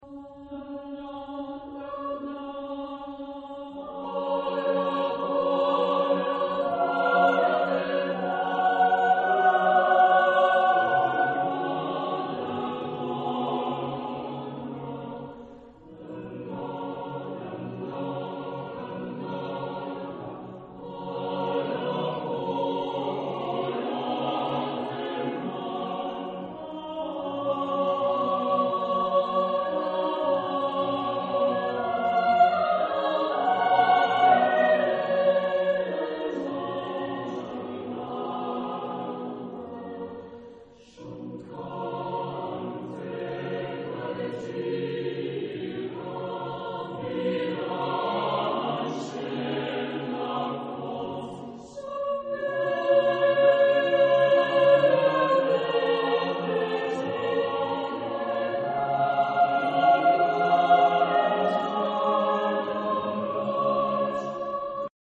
Genre-Style-Forme : Ballade
Type de choeur : SAATTBB  (7 voix mixtes )
Tonalité : fa majeur